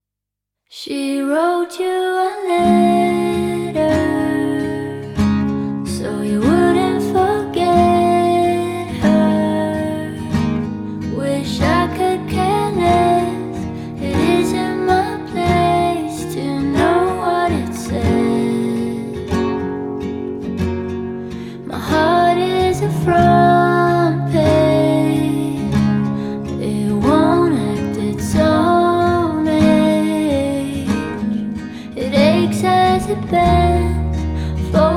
Indie Pop Alternative
Жанр: Поп музыка / Альтернатива